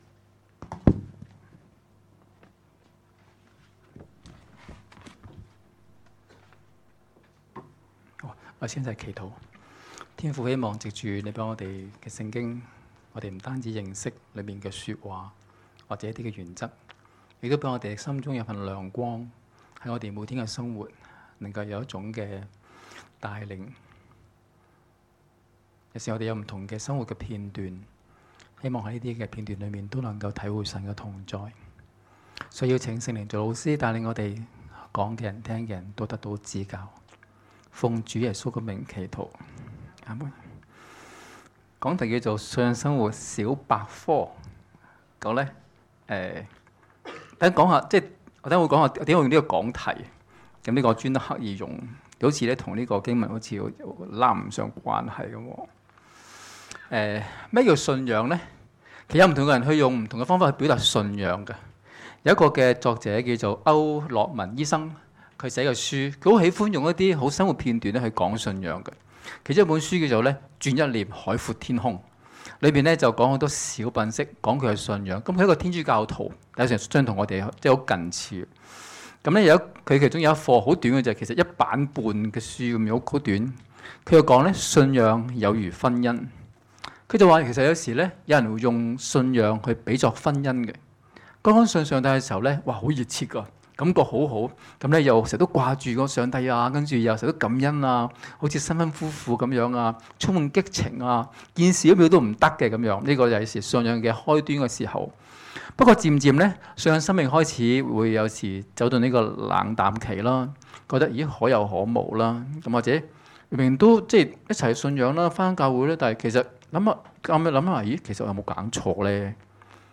2016年10月22日及23日崇拜講道